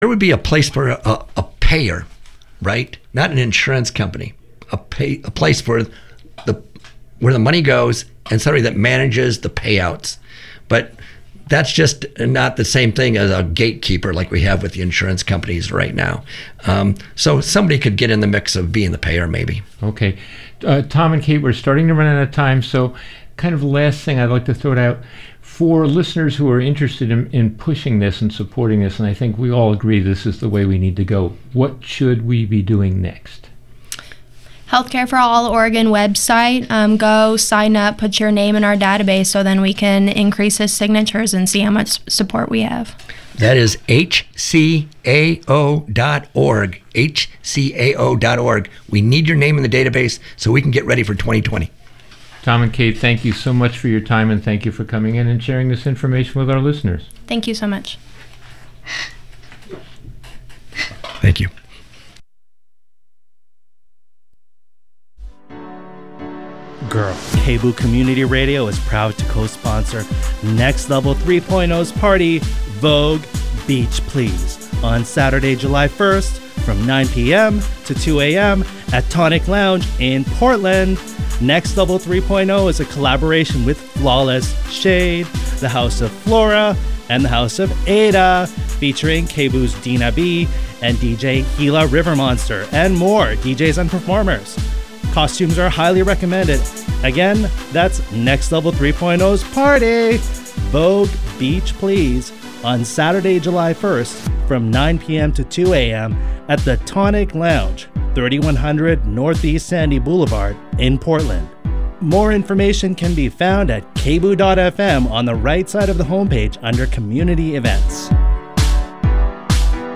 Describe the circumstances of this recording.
On Apri 7th at PSU KBOO had the pleasure to record a presentation